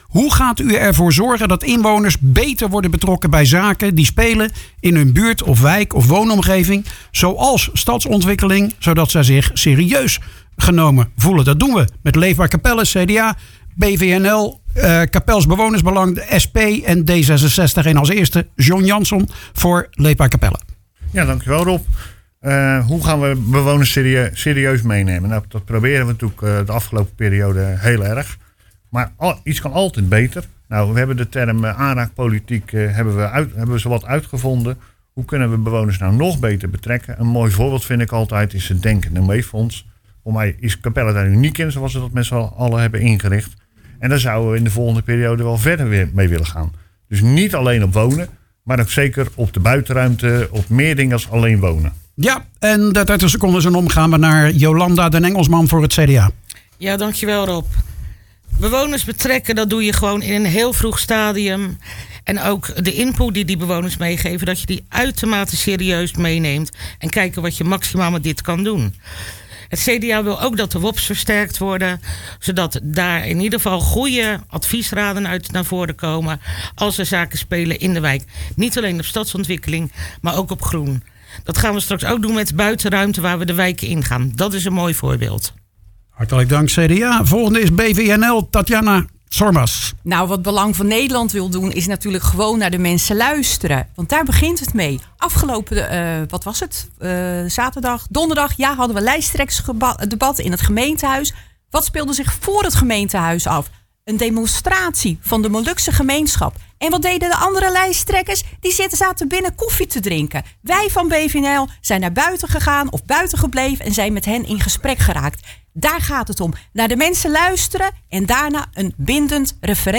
Op zaterdag 12 maart was in de studio van Radio Capelle het laatste verkiezingsdebat voordat de stembureaus opengaan.